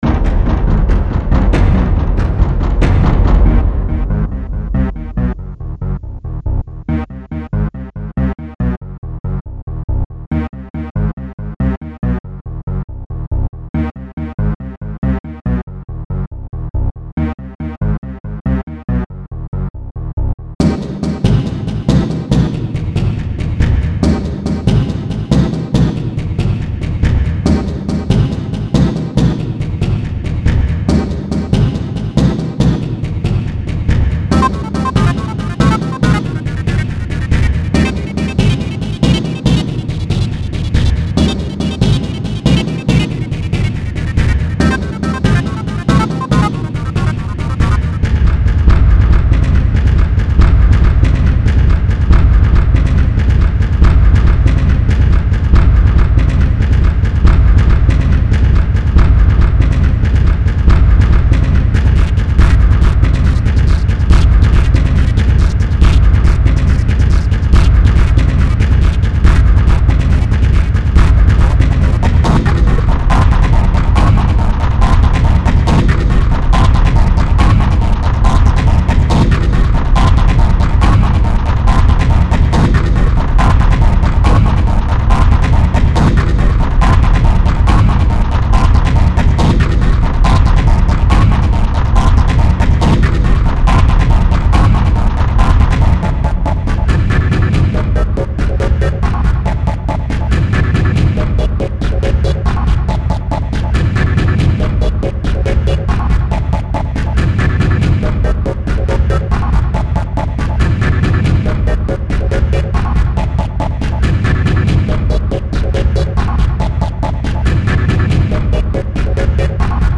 Enemy Spotted juggles about 3-4 different themes, which is nicer to listen to, but there's very little logical structure in it.